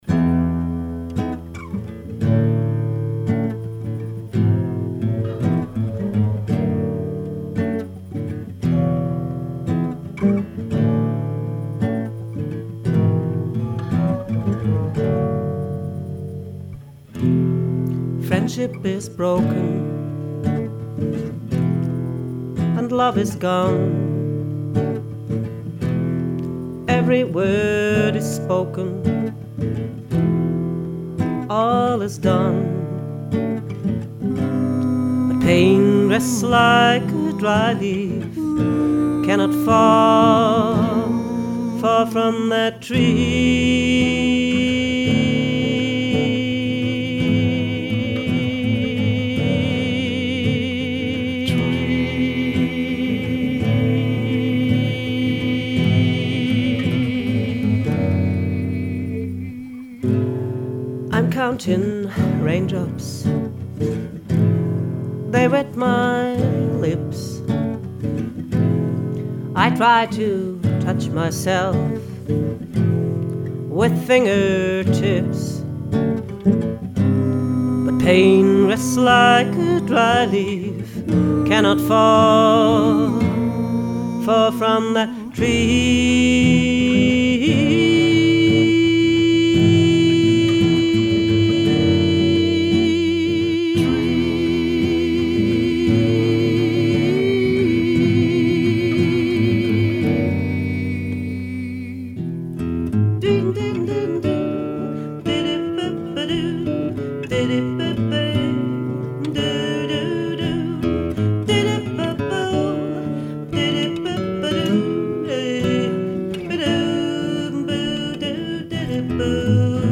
vocals, guitar